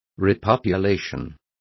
Complete with pronunciation of the translation of repopulation.